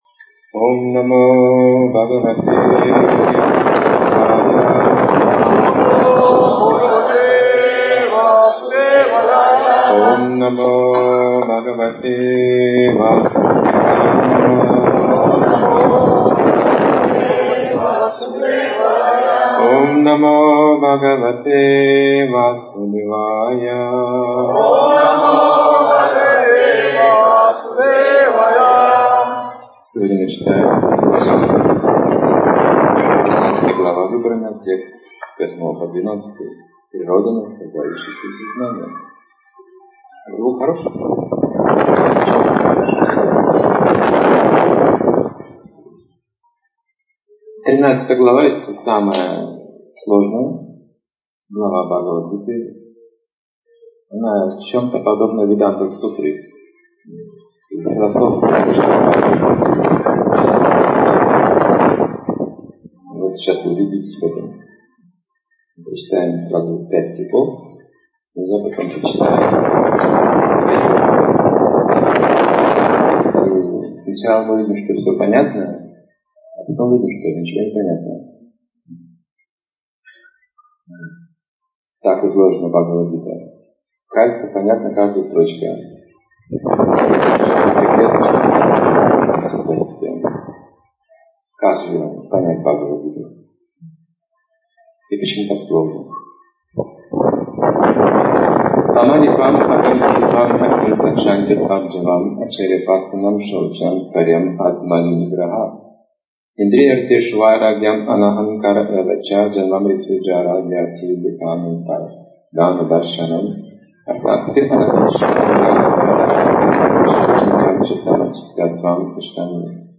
Темы, затронутые в лекции: Настоящее поле деятельности Дух сознания Драгоценный дар Пробуждение истинного знания История об императоре Юдхиштхире о приближении века Кали Деятельность иллюзорной энергии Желание вечно служить Кришне Возрождение своей духовной природы Дорога ведущая в ад Как избавится от сомнений и материальных желаний Клубок материального существование Отказ от насилия Сбитая с толку